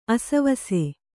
♪ asavase